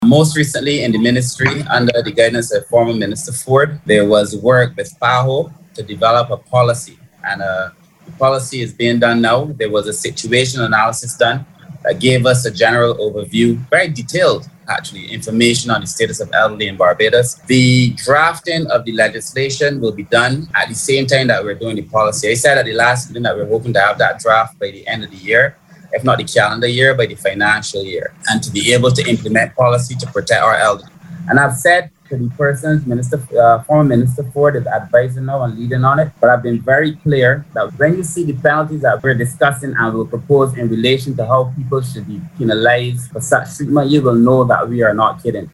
Voice: Minister of People Empowerment and Elder Affairs, Kirk Humphrey.